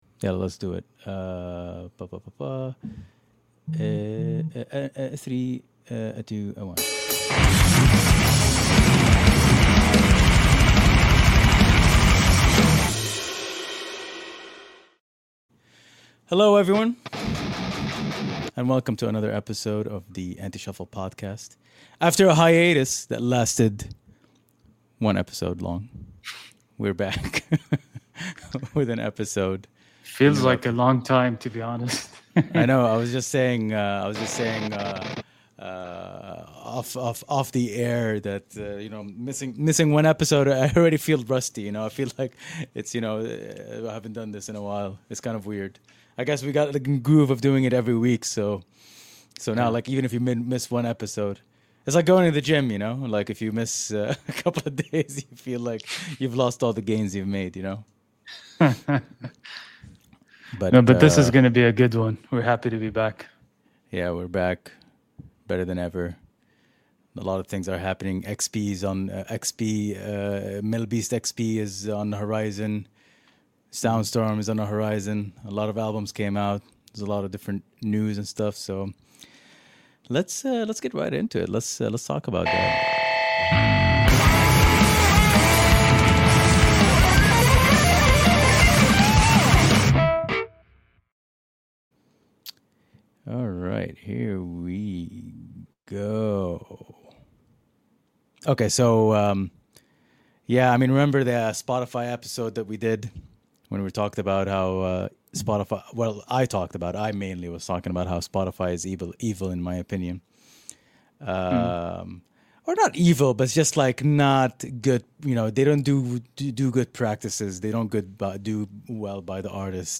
The Anti-Shuffle Podcast is a weekly rock and metal podcast hosted by Saudi-based musicians